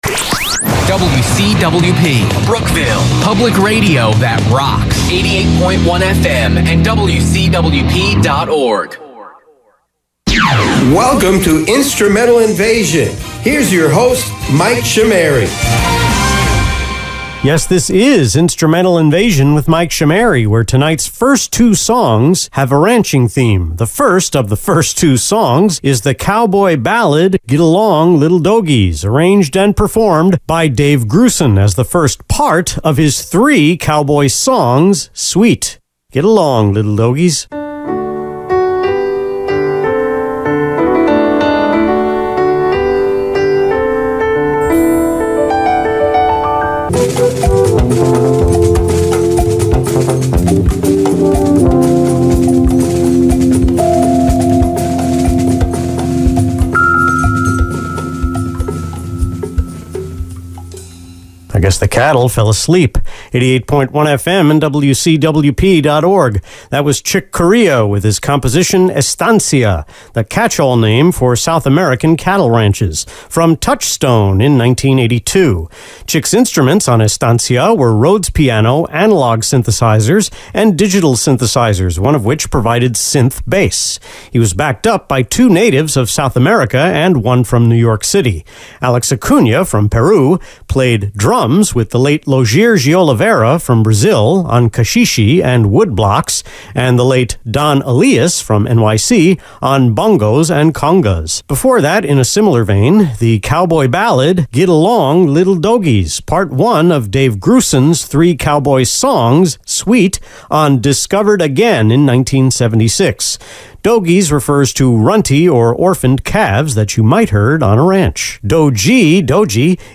One segment was recorded on May 9, three on the 10th, and two (plus a pickup) on the 11th.
The talk break scripts for the first two segments were drafted before recording each of them, with the rest of the script drafted before recording the rest of the segments.